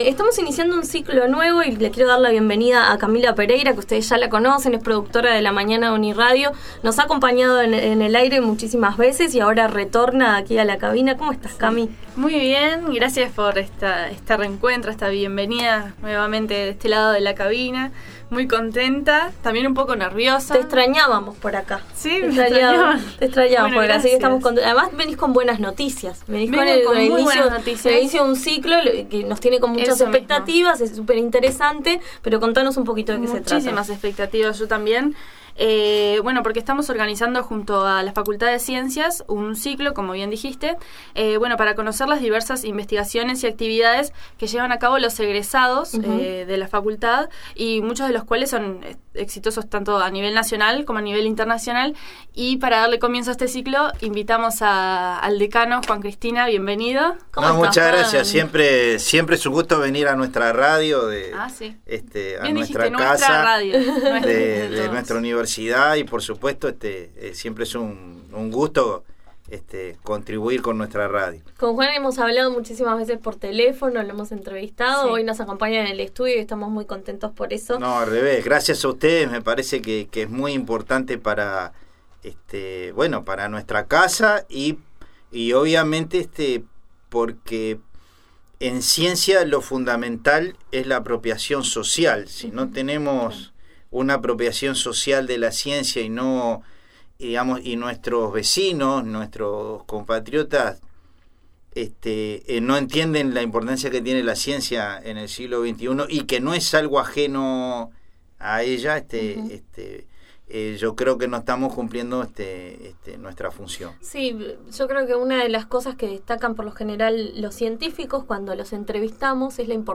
En diálogo con la Nueva Mañana nos contó cuáles son los desafíos actuales a los que se enfrenta la facultad, cuál es la oferta académica con la que se cuenta y si considera que todavía hay dificultades para insertar a los egresados en ciencias en empleos públicos.